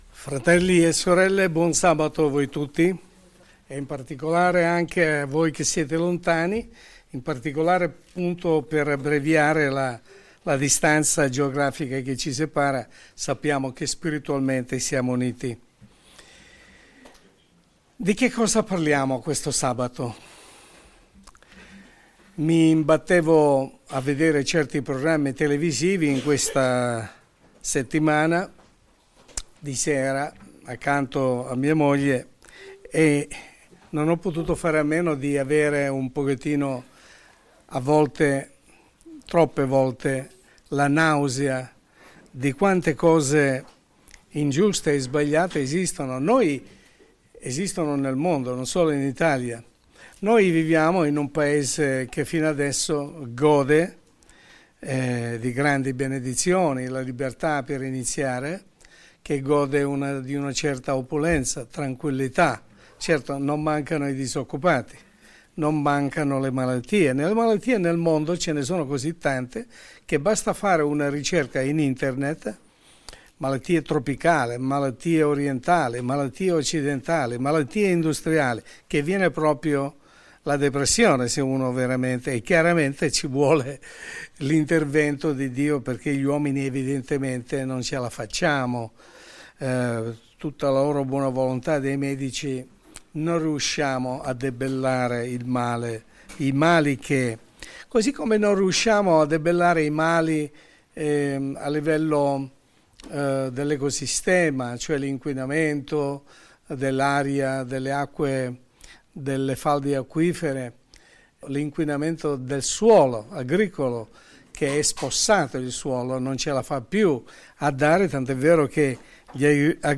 Messaggio pastorale